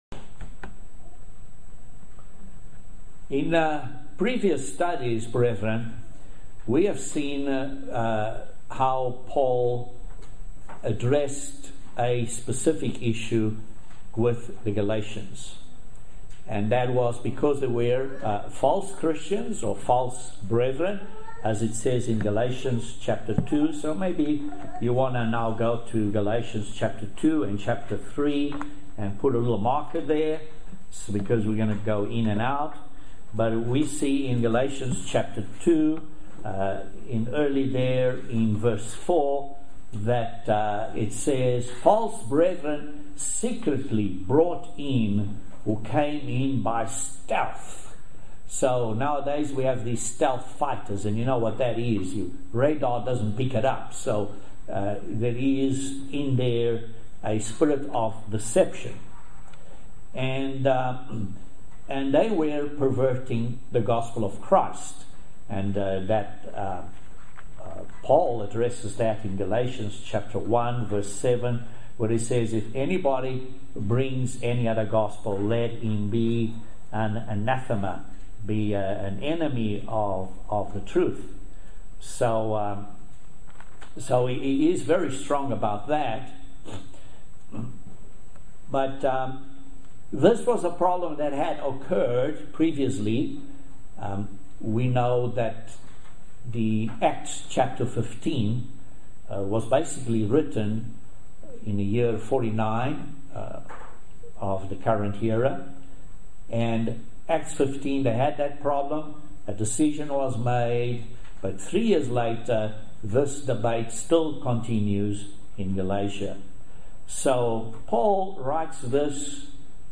Join us for this very interesting Video sermon study on the subject of Paul's Epistles. This is a series on Paul's Epistles.